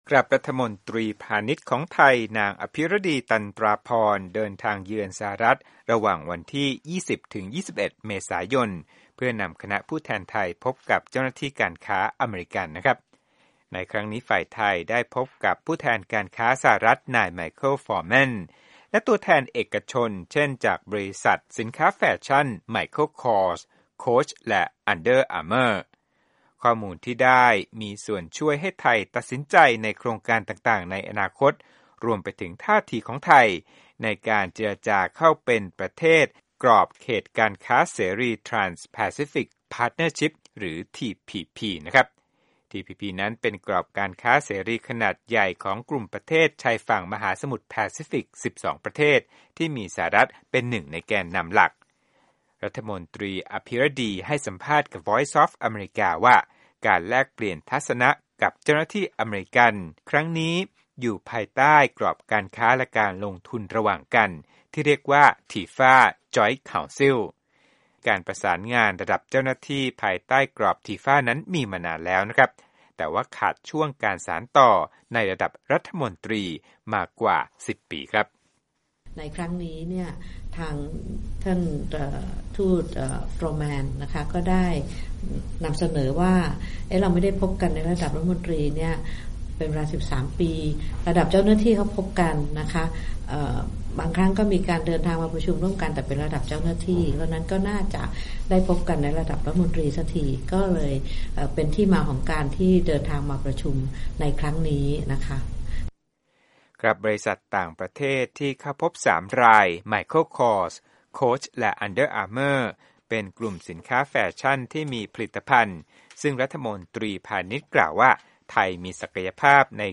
VOA Thai interviewed with Thailand Minister of Commerce, Apiradi Tantraporn at Washington,DC.
Interview Commerce Minister